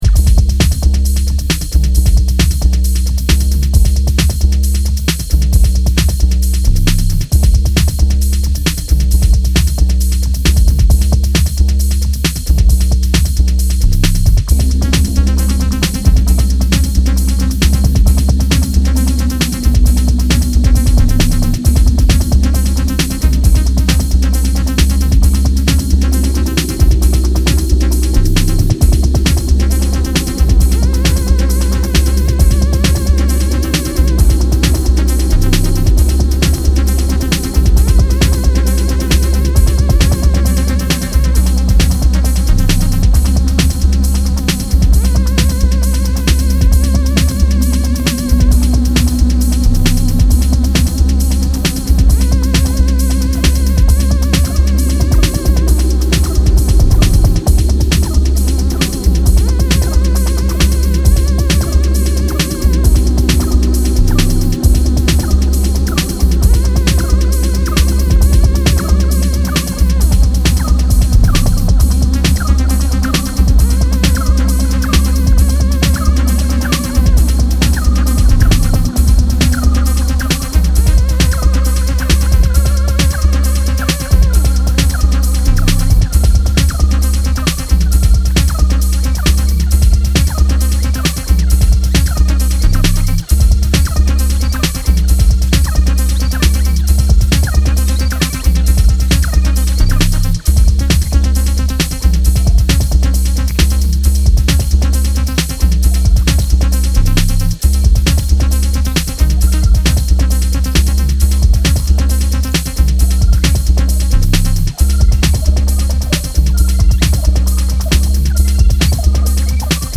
Raw booming electro cuts
Electro